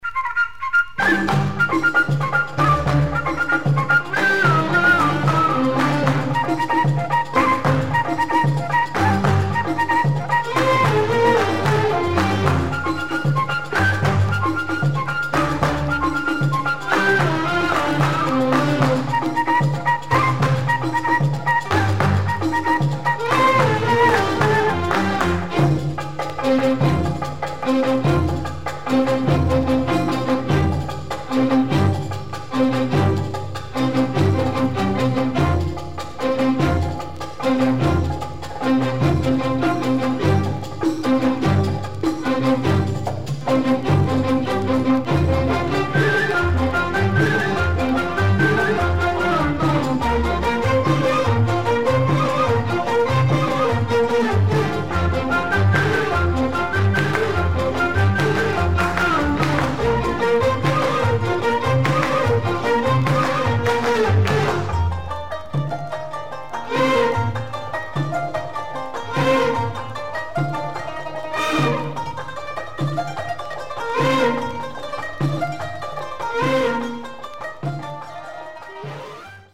it sounds more Lebanese / Egyptian.